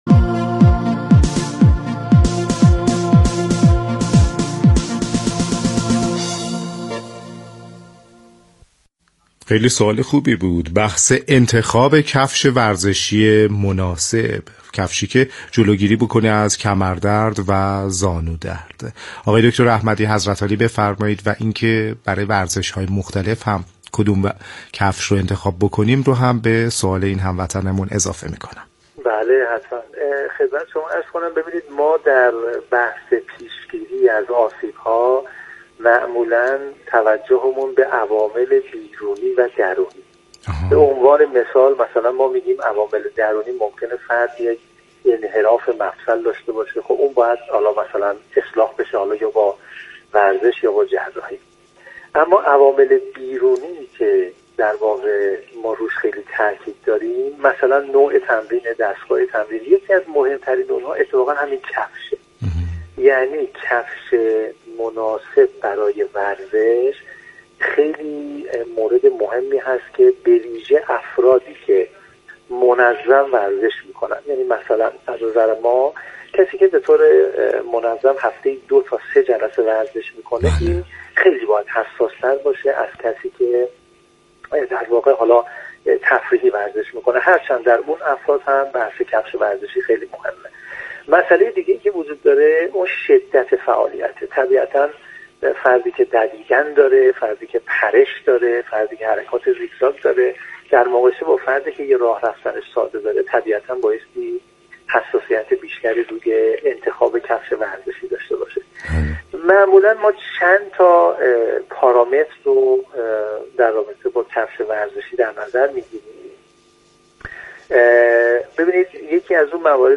/صوت اموزشی/
در گفت وگو با برنامه نسخه ورزشی رادیو ورزش